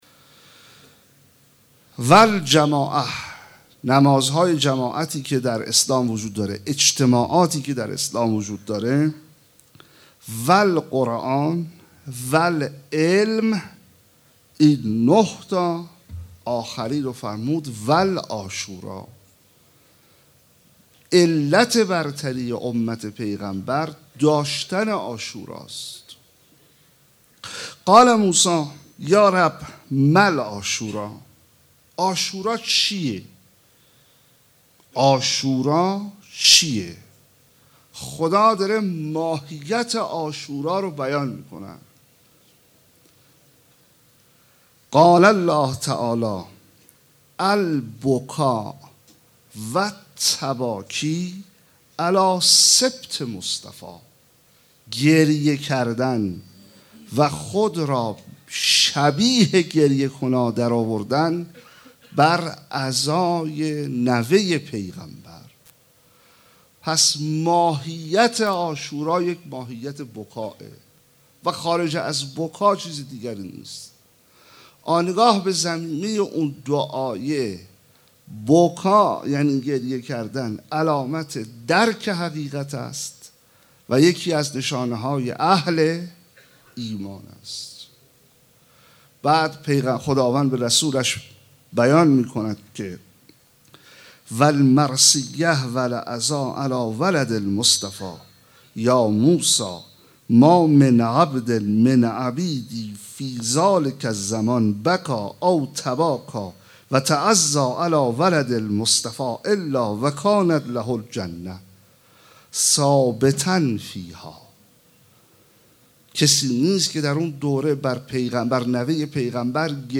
شب پنجم محرم 1436 - هیات رایه العباس B > سخنرانی